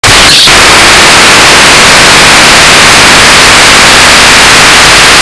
Audacity is only picking up this extremely loud noise and I don't know how to fix it
Nothing comes through but this stupid noise. I only want to record something with my guitar through a sound card but anything I try to record just ends up like this sausage.